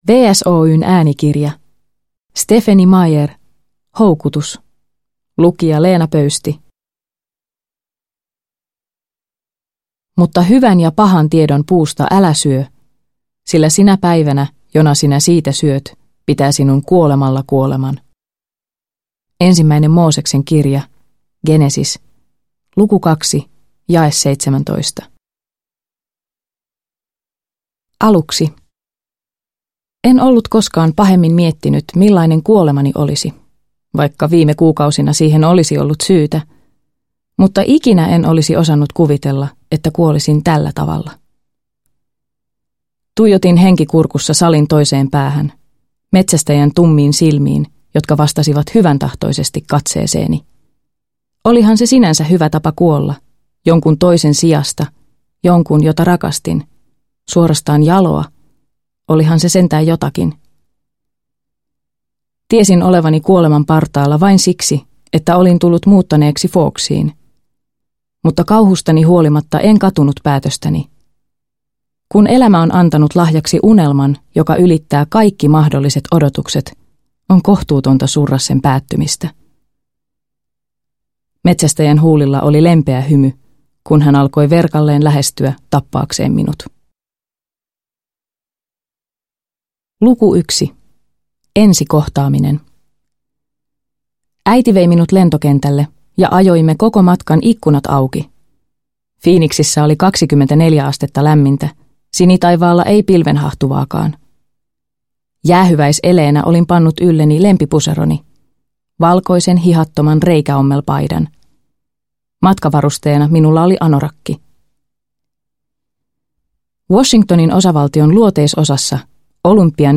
Houkutus – Ljudbok – Laddas ner